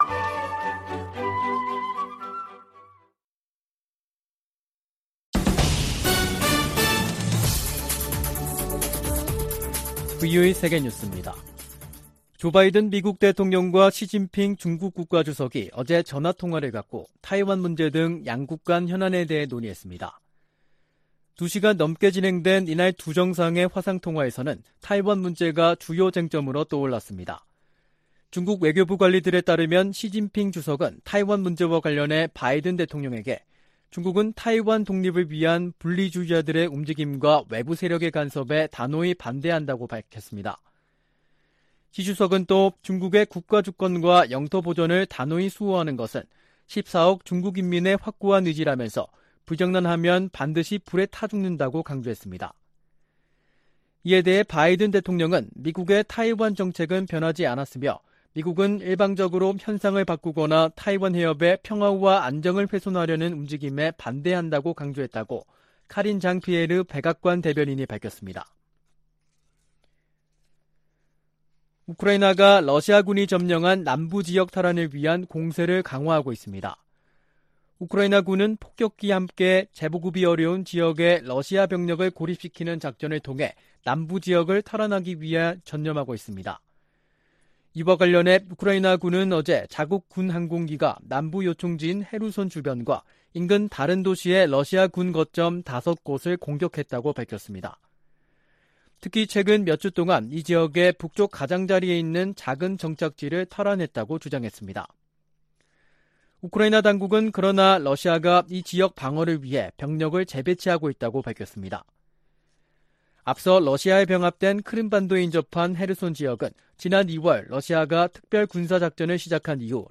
VOA 한국어 간판 뉴스 프로그램 '뉴스 투데이', 2022년 7월 29일 2부 방송입니다. 미 국무부는 김정은 국무위원장의 전승절 기념행사 연설에 직접 반응은 내지 않겠다면서도 북한을 거듭 국제평화와 안보에 위협으로 규정했습니다. 핵확산금지조약(NPT) 평가회의에서 북한 핵 문제가 두 번째 주부터 다뤄질 것이라고 유엔 군축실이 밝혔습니다. 백악관 고위 관리가 북한이 미사일 자금 3분의 1을 사이버 활동으로 마련한다며 대응을 강화할 것이라고 말했습니다.